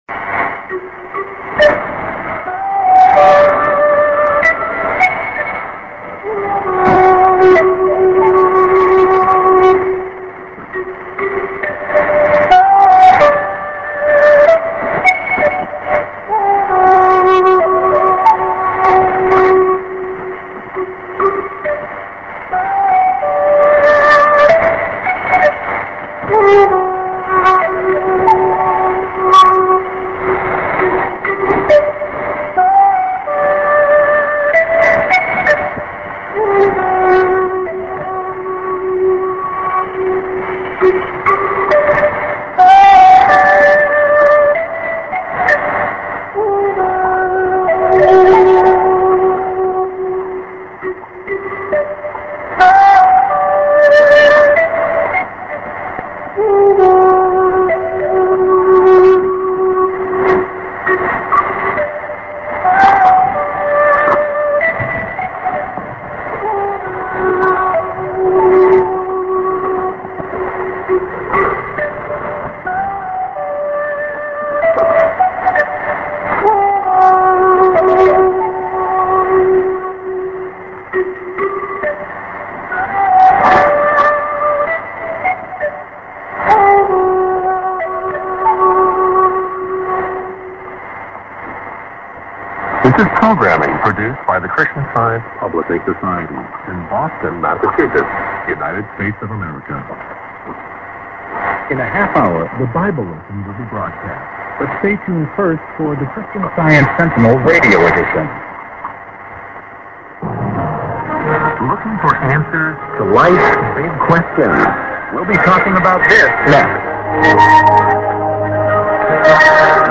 IS->ID(man)->prog | via Irkutsk Russia